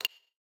tabSelection.wav